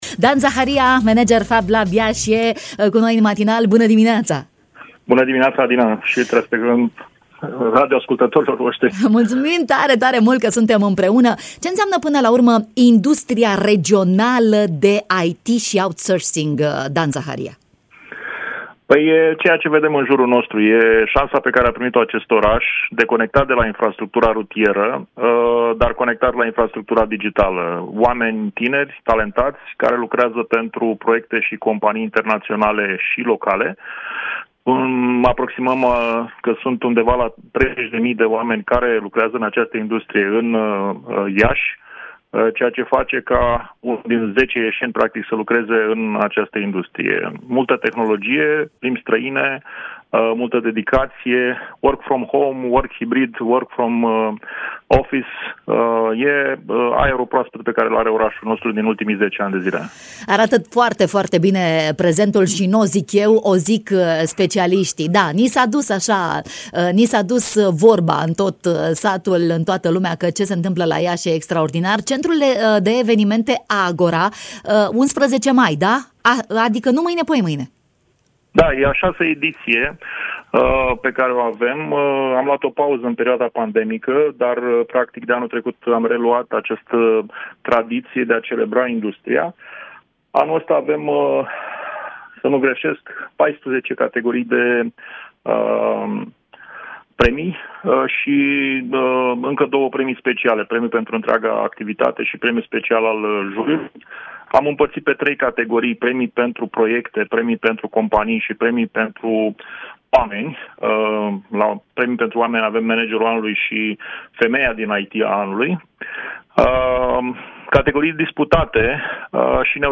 în direct la Radio Iași